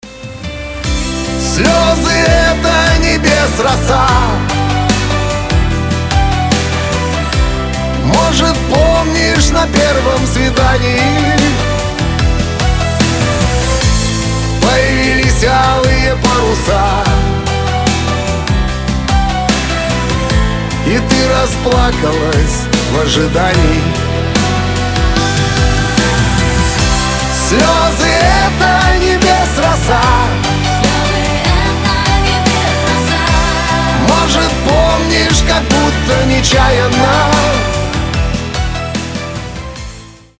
громкие
душевные
ностальгия